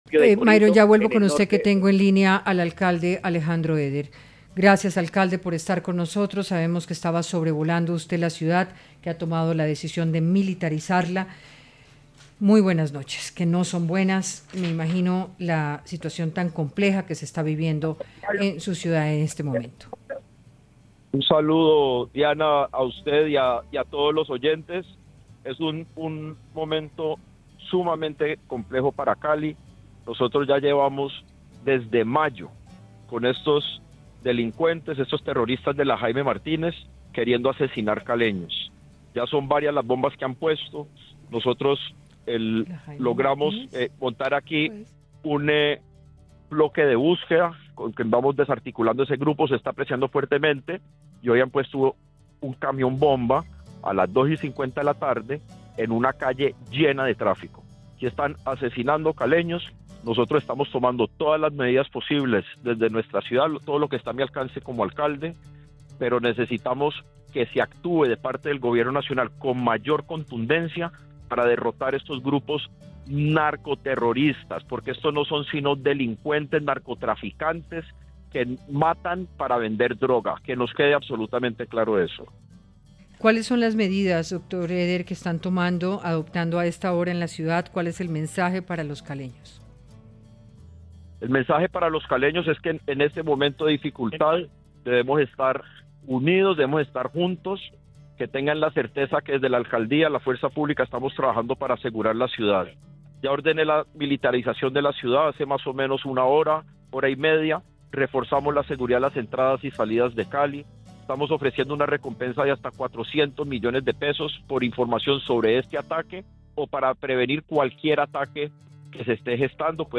En diálogo con Hora20 de Caracol Radio, el alcalde de Cali Alejando Eder, aseguró que aunque no sabe si el decreto de conmoción interior es una decisión tomada o un simple rumor, del que no ha sido notificado, cree que la conmoción no es necesaria ni conveniente, “lo que se necesita es activar la fuerza pública y darle recursos para atender la amenaza terrorista, pero no se necesita una declaración de conmoción interior, eso lleva a suspender el orden jurídico de las cosas y no estamos en una situación de anarquía.